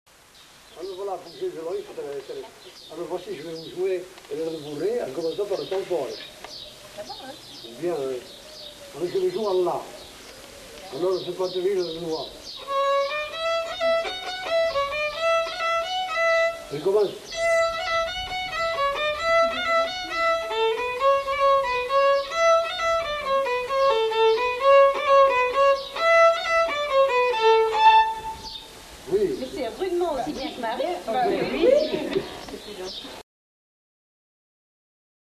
Aire culturelle : Couserans
Département : Ariège
Genre : morceau instrumental
Instrument de musique : violon
Danse : bourrée